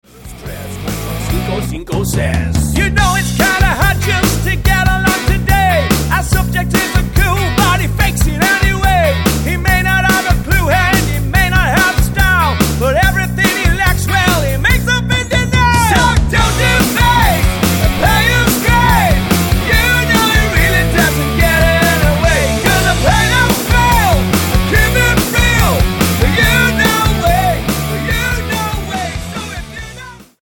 Tonart:B Multifile (kein Sofortdownload.
Die besten Playbacks Instrumentals und Karaoke Versionen .